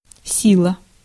Ääntäminen
IPA: /ˈsʲilə/